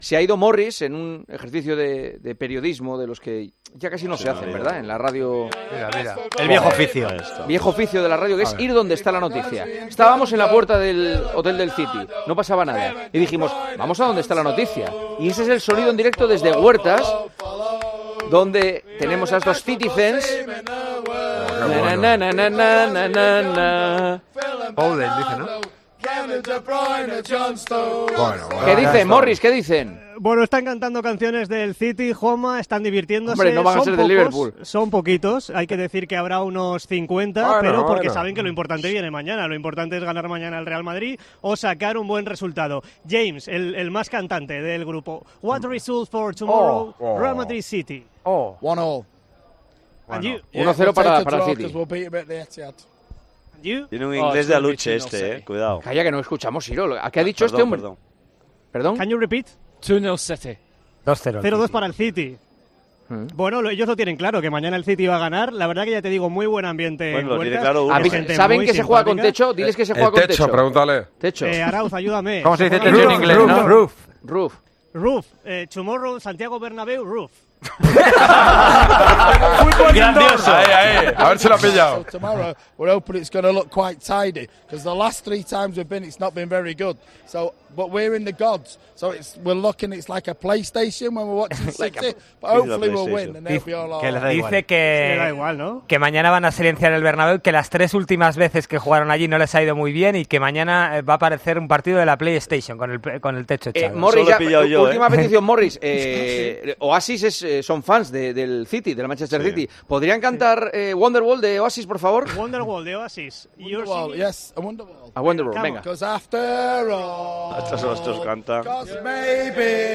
Algunas decenas de aficionados del Manchester City decidieron ir cogiendo calor de cara al partido de ida de los cuartos de final de la Liga de Campeones frente al Real Madrid en el estadio Santiago Bernabéu desde el día anterior por las calles del centro de la capital española.
Algunos, incluso, se atrevieron a cantar, para sorpresa de Juanma Castaño, el mítico tema 'Wanderwall', de Oasis.